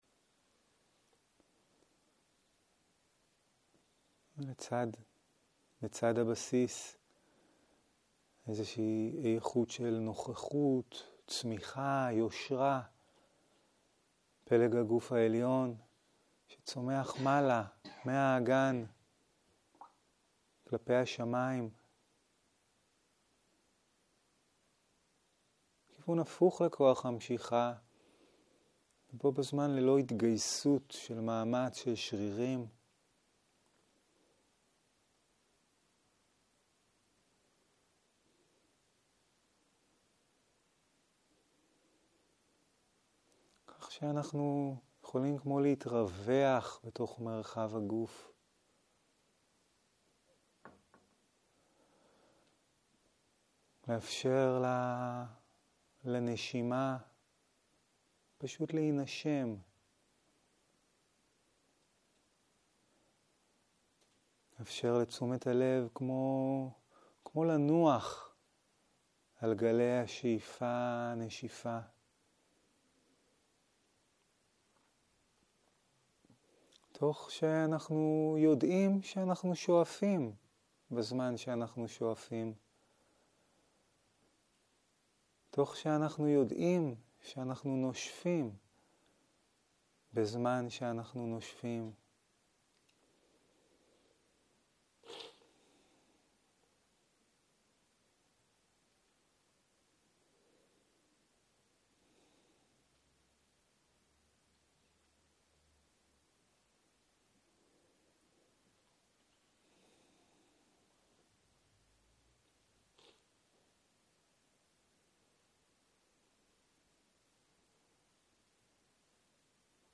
14.01.2023 - יום 2 - צהרים - מדיטציה מונחית - הקלטה 3
Dharma type: Guided meditation שפת ההקלטה